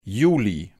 Begriffe von Hochdeutsch auf Platt und umgekehrt übersetzen, plattdeutsche Tonbeispiele, Schreibregeln und Suchfunktionen zu regelmäßigen und unregelmäßigen Verben.